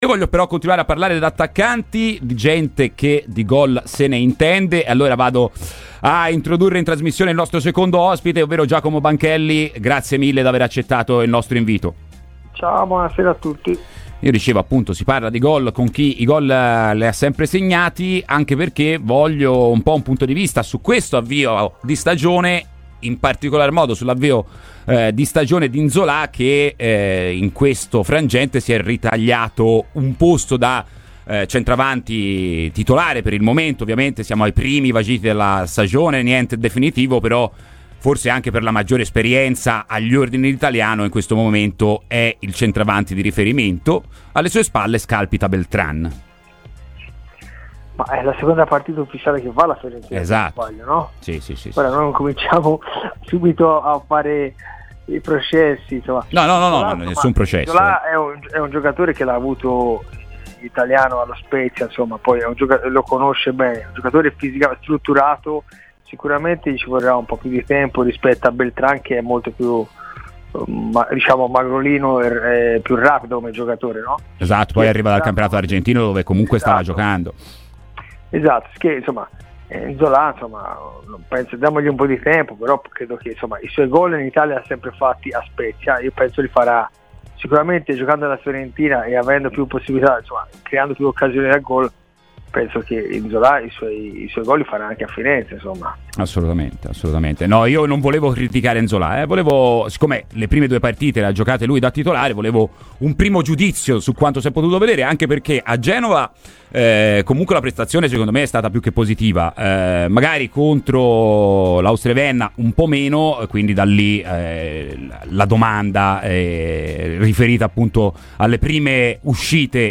ex viola, parla così dei principali temi di casa Fiorentina ai microfoni di Radio FirenzeViola .